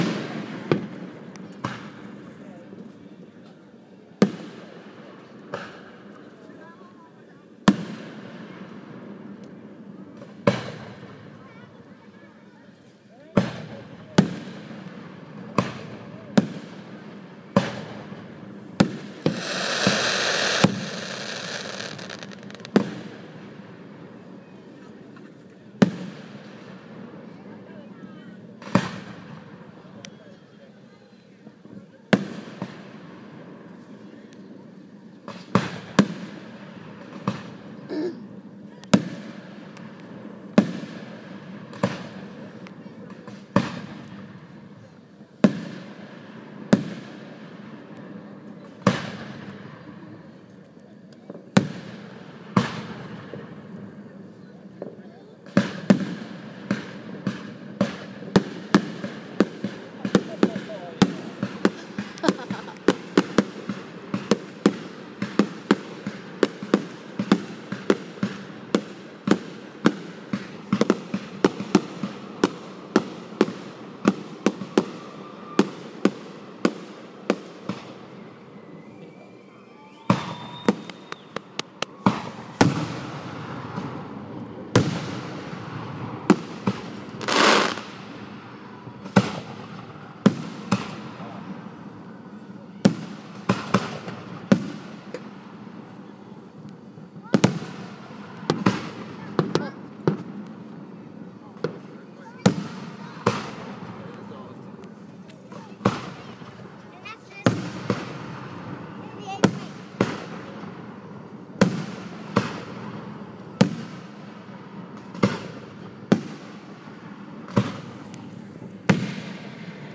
Happy Independence Day fireworks show for those who couldn't attend one. :-) part 3